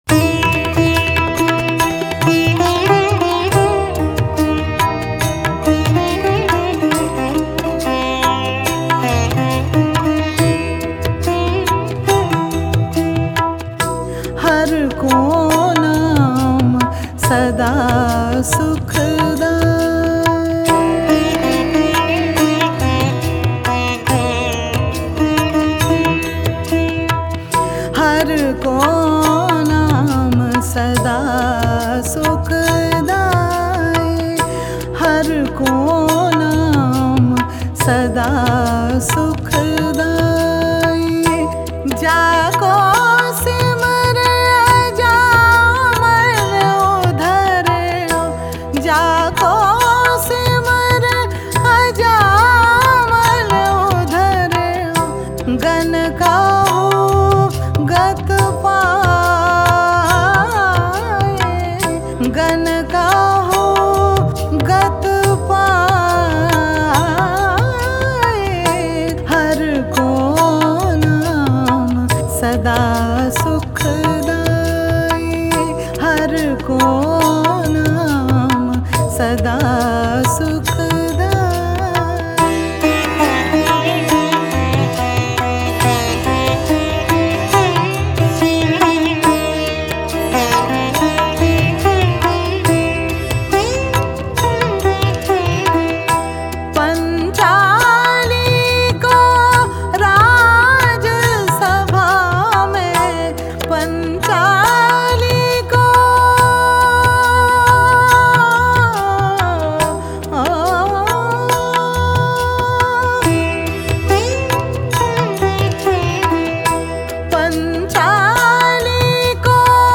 शास्त्रीय संगीत